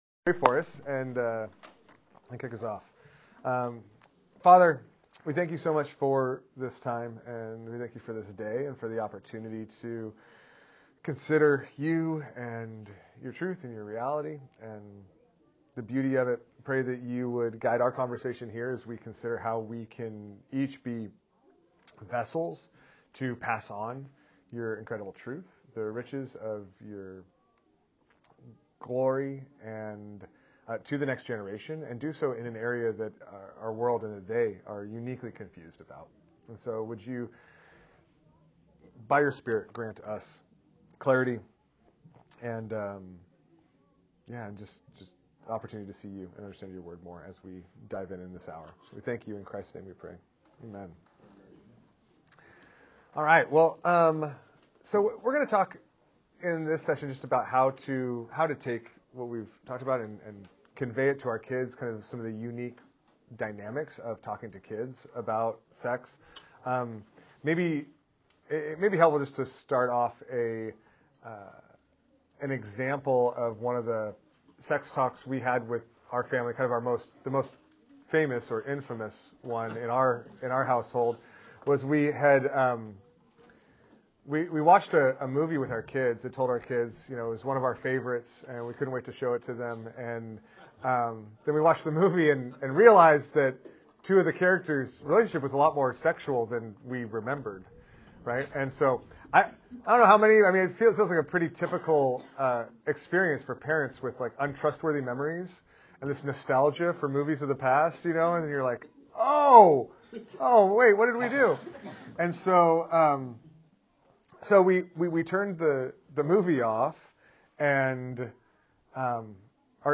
Sermons from Grace
body-life-conference-2025-how-to-talk-to-your-children-about-sex-session-4.m4a